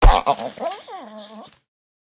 Minecraft Version Minecraft Version latest Latest Release | Latest Snapshot latest / assets / minecraft / sounds / mob / wolf / sad / death.ogg Compare With Compare With Latest Release | Latest Snapshot
death.ogg